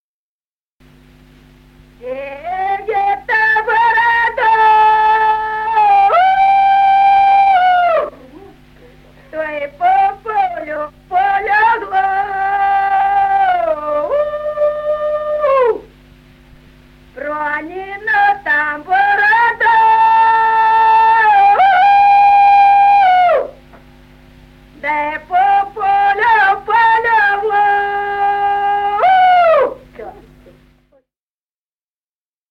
Народные песни Стародубского района «Чия это борода», пожиночная.
с. Остроглядово.